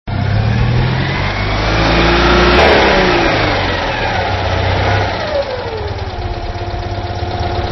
Vroum... real media    (durée  12" MP3)  Le moulin... le bruit du moteur...